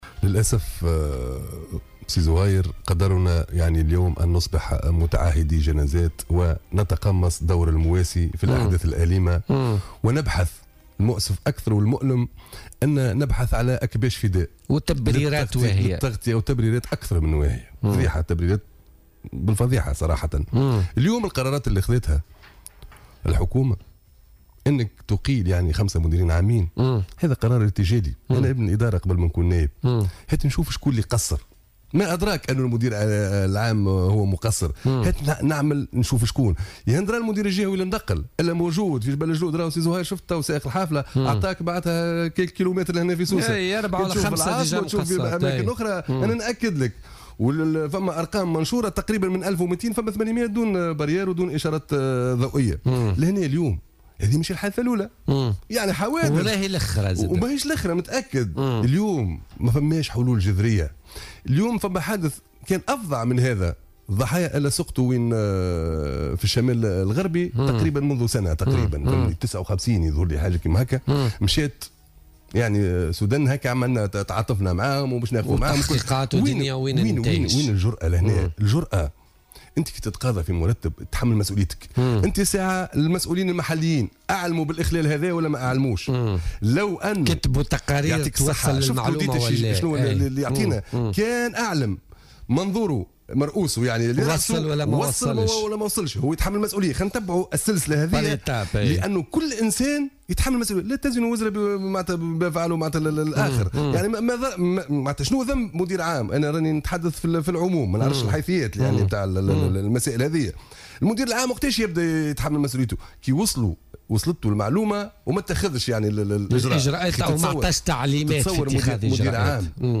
وأضاف ضيف "بوليتكا" اليوم الخميس أن إقالة رئيس الحكومة لـ5 مديرين عامين اثر الحادث قرار ارتجالي، معتبرا إياهم "أكباش فداء".